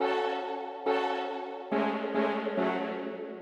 Metro_loopC#m (1).wav